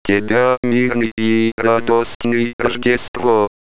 [You can click the greeting to hear a voice synthesizer say this greeting in Russian--NOTE: I realized after sending this letter out to all my family and friends, that I actually have a grammatical error in the Russian greeting; all those "bl"s and backwards "N"s should be "o"s and "e"s, because "Christmas" is a neuter word, rather than a masculine one, in Russian.]
christmas.wav